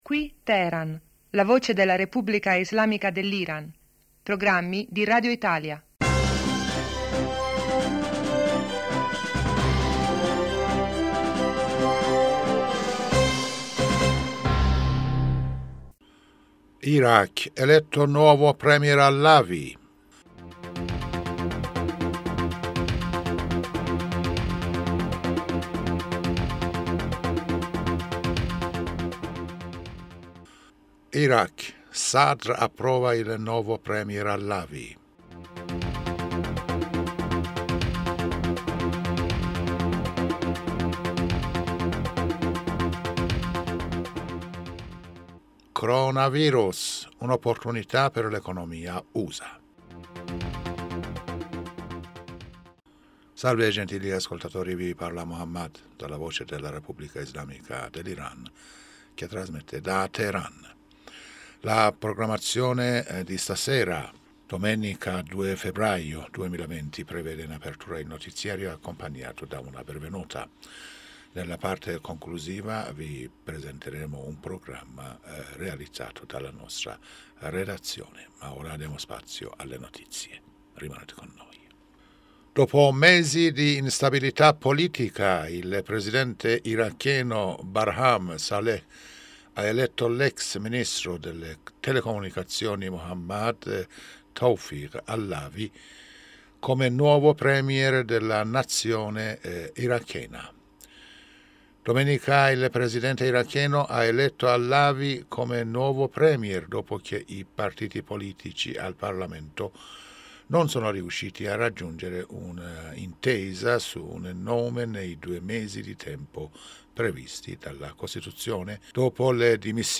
Giornale radio 2020-02-02